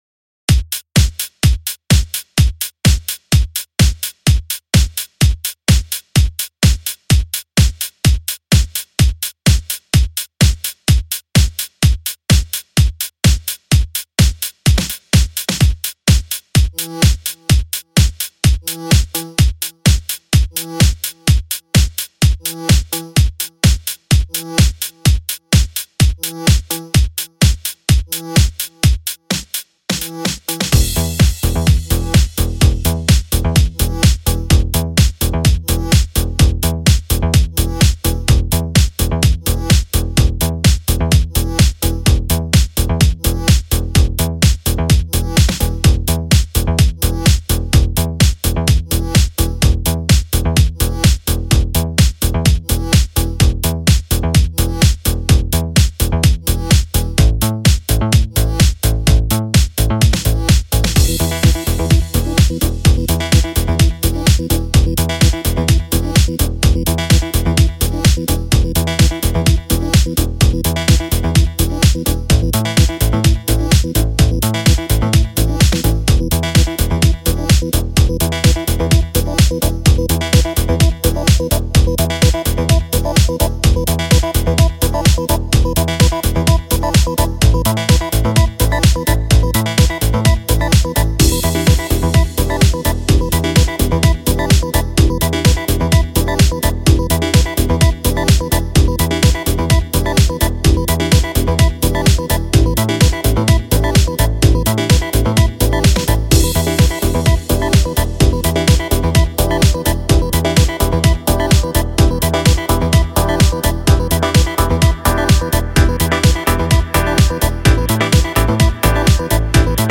Bubble (zwischen Deep und Techno)
Nachdem ich die Kick und den Subbass fertig hatte, verselbständigte sich der Prozess.